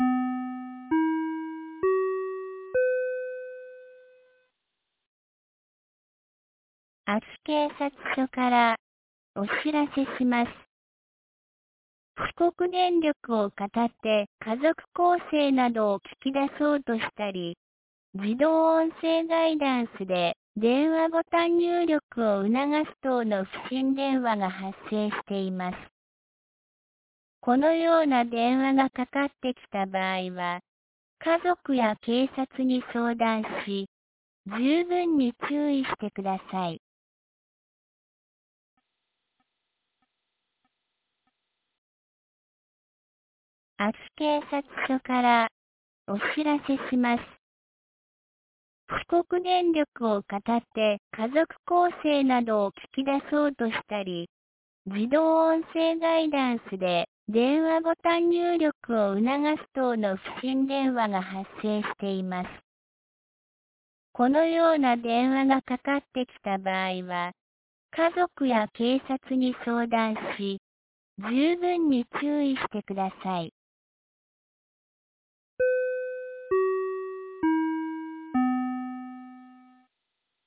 2024年10月24日 12時54分に、安芸市より全地区へ放送がありました。